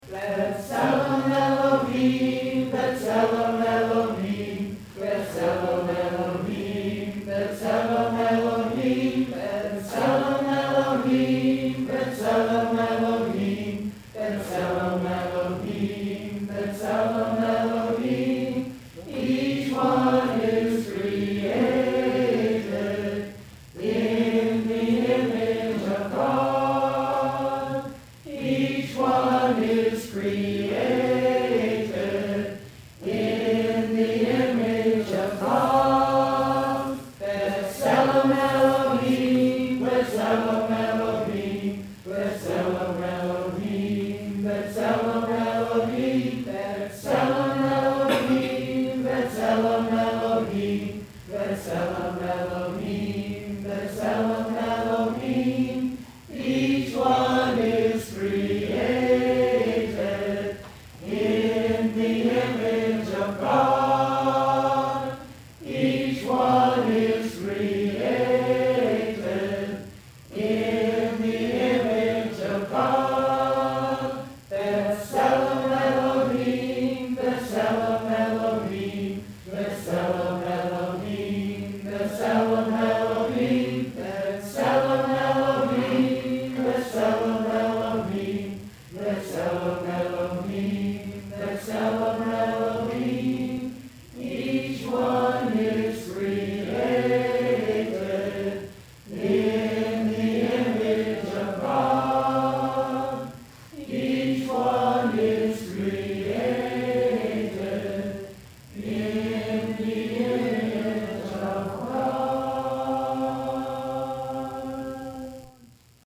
in several Hebrew chants during a Sunday morning worship service on February 17, 2008.*
*All chants were recorded live at Mill Valley Community Church, Mill Valley, California, February 17, 2008.